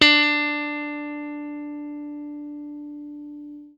FENDRPLUCKAJ.wav